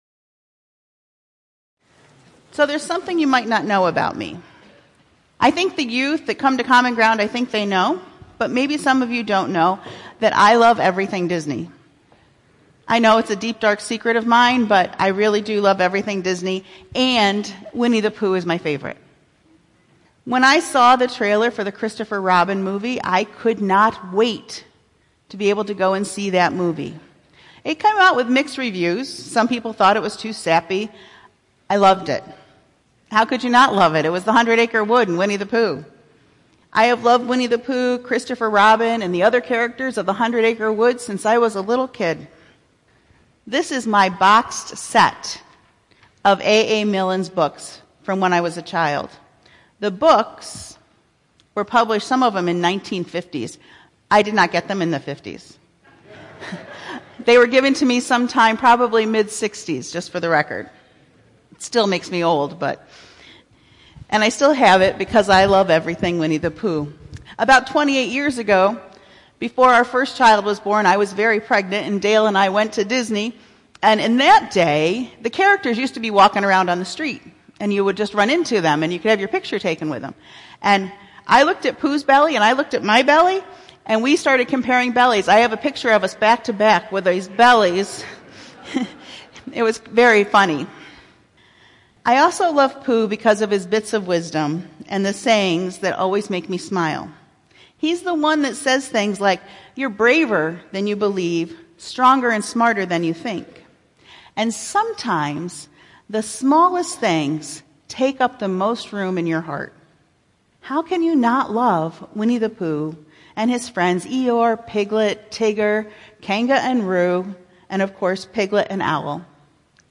2019-02-24 Sermon, “Sabbath Joy”
Feb2419-Sermon.mp3